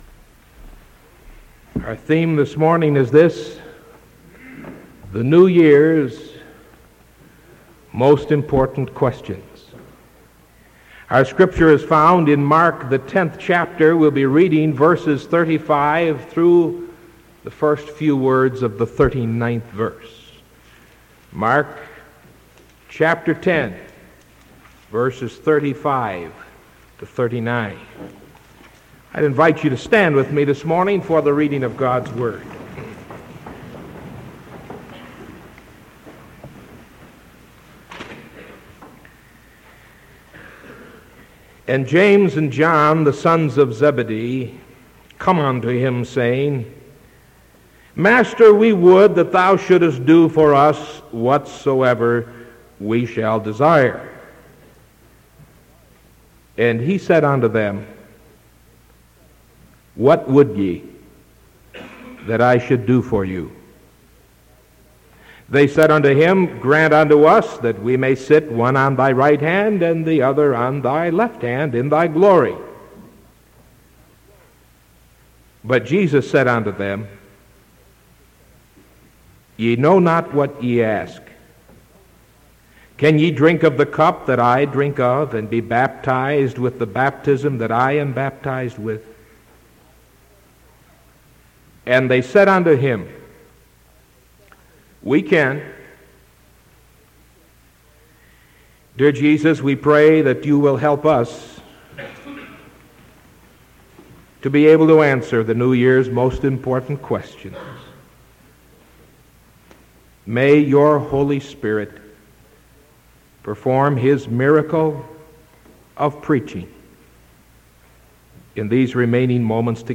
Sermon from December 28th 1975 AM